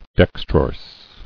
[dex·trorse]